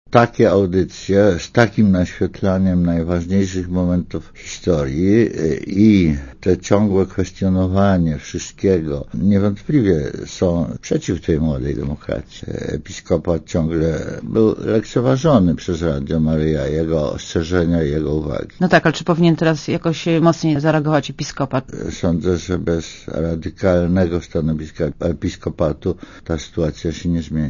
Mówi Tadeusz Mazowiecki
Sądzę, że bez radykalnego stanowiska Episkopatu, ta sytuacja się nie zmieni - powiedział Mazowiecki w Radiu Zet.